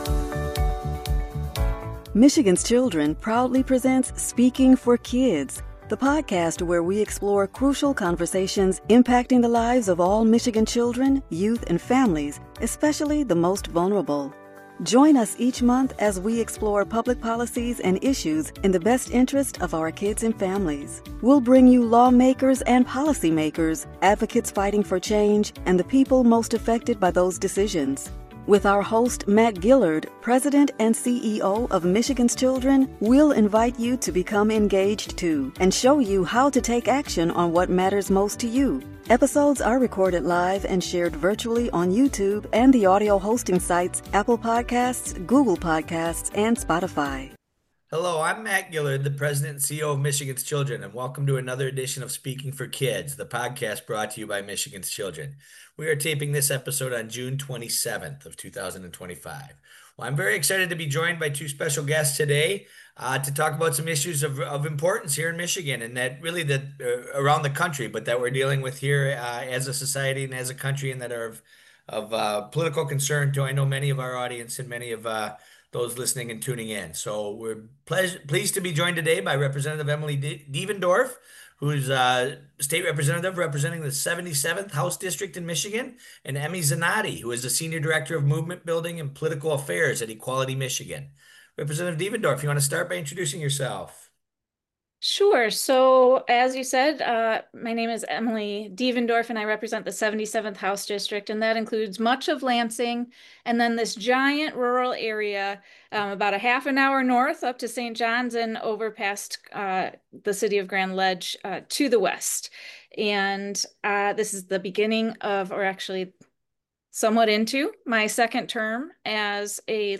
Each month, Speaking for Kids the podcast will bring you a variety of crucial conversations with people making public policy and the voices of the people impacted by those decisions in Michigan. Our goal will be to help you strengthen your voice to speak up for the changes we need to improve Michigans kids' & families' lives.